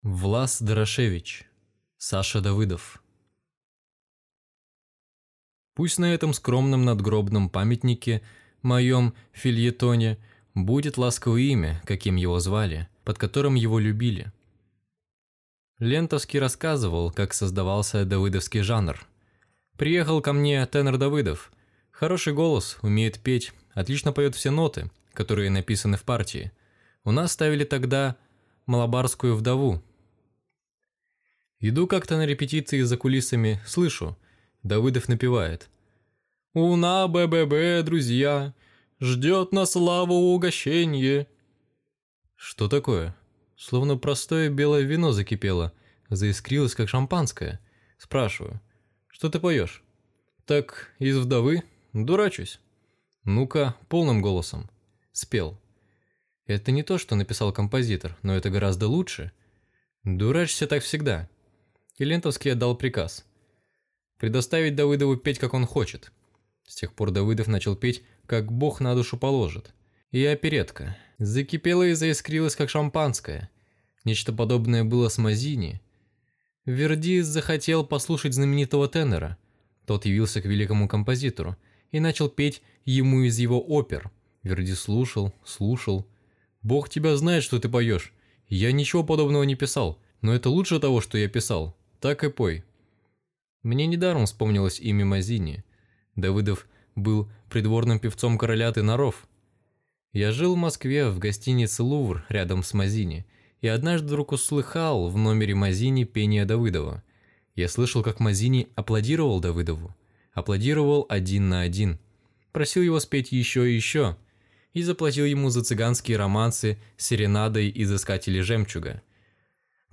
Аудиокнига «Саша Давыдов» | Библиотека аудиокниг